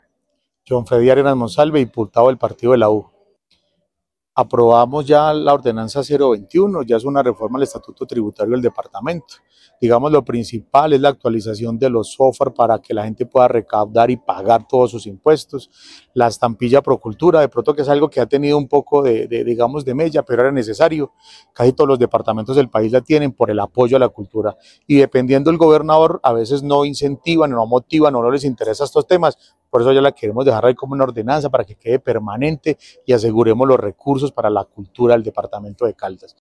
Jhon Fredy Arenas Monsalve, diputado de Caldas
John-Fredy-Arenas-Monsalve-diputado-de-Caldas.-1.mp3